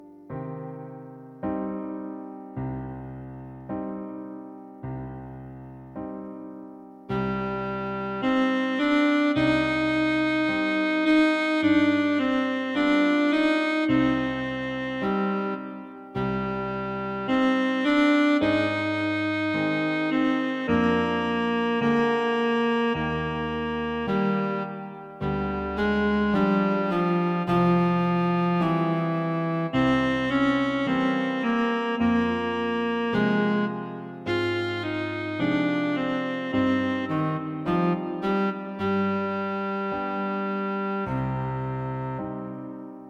A most beautiful melody.
Arranged for solo Cello and piano.
Bassoon Solo and Piano A most beautiful melody.